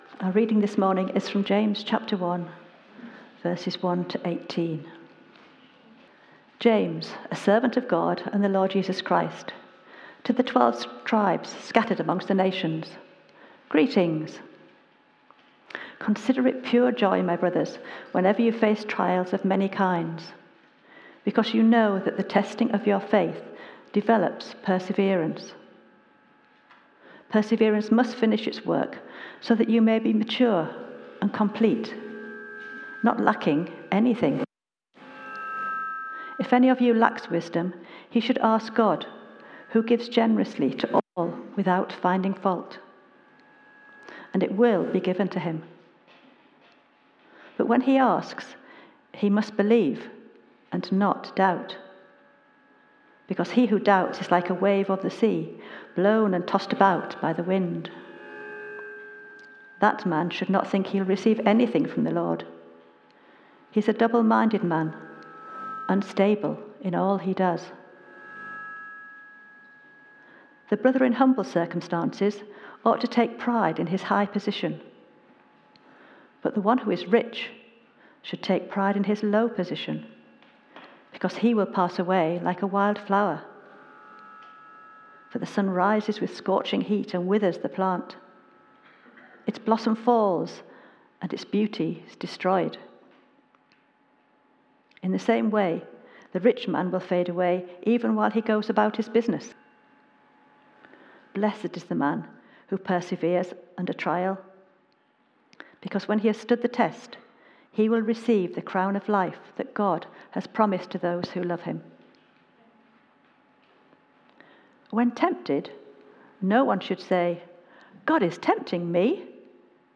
James 1:1-18 Series: James: Faith in Action - Real Faith, Real Life. Theme: Intro & Joy in the Midst of Trials Sermon To find a past sermon use the search bar below You can search by date, sermon topic, sermon series (e.g. Book of the Bible series), bible passage or name of preacher (full or partial) .